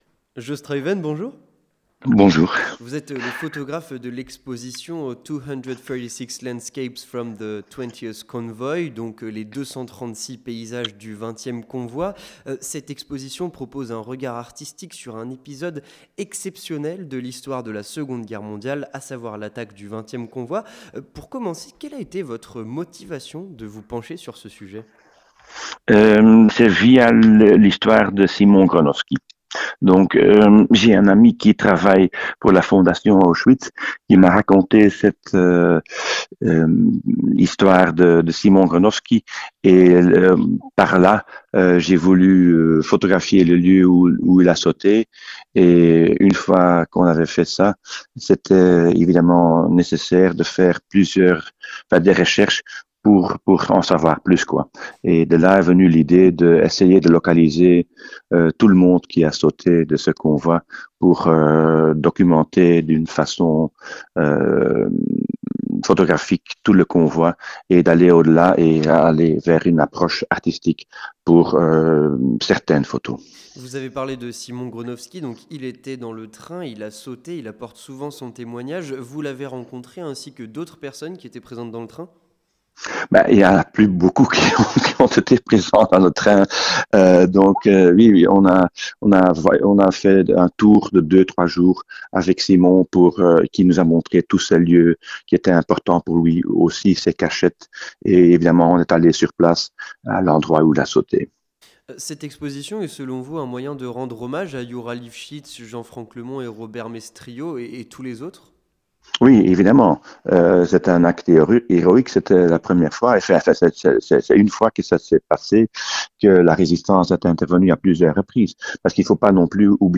répondait aux questions de Radio Judaïca.